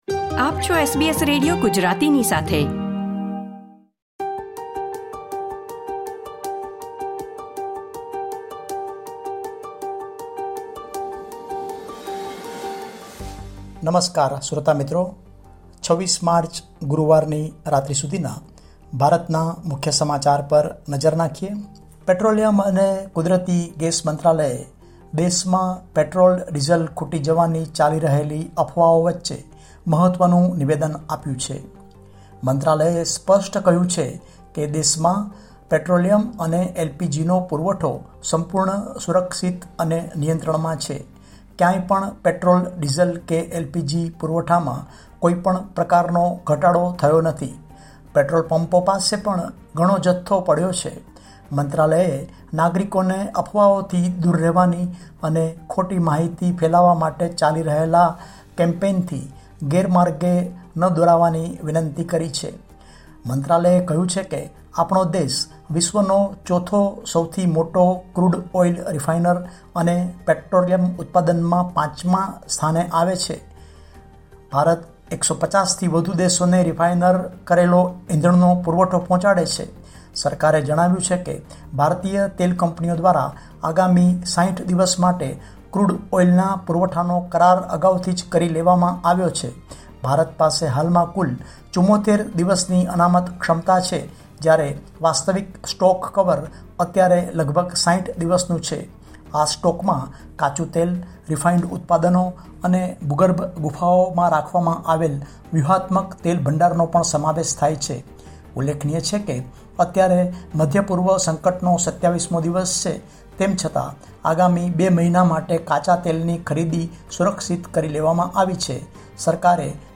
Listen to the latest Indian news from SBS Gujarati.